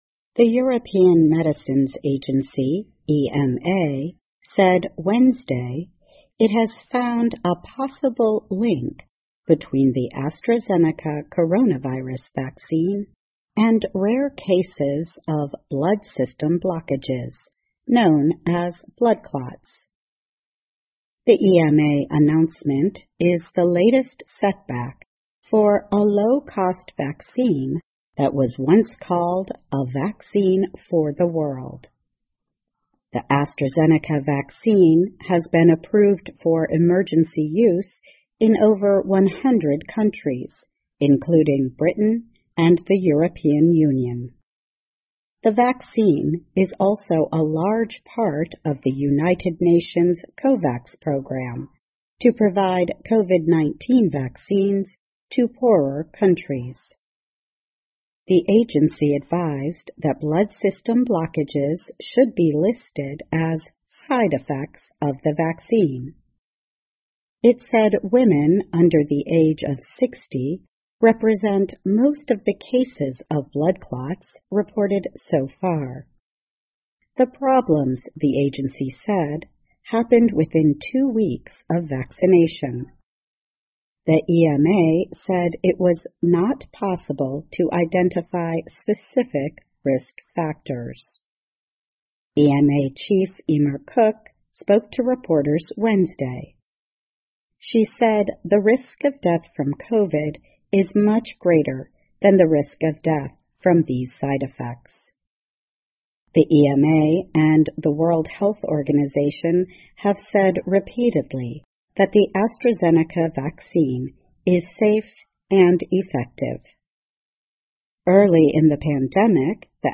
VOA慢速英语2021 欧洲药管局称可能发现血栓同阿斯利康疫苗之间的联系 听力文件下载—在线英语听力室